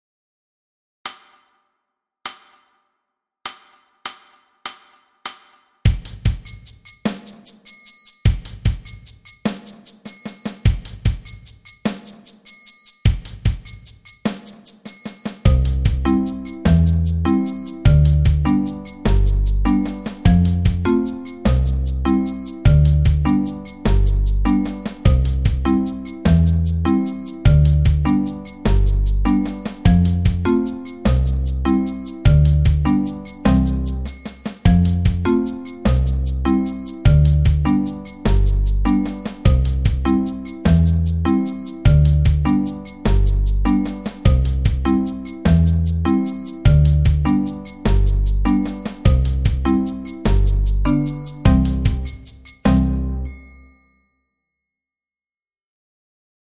Danses de l’ours d’or – accompagnement à 100 bpm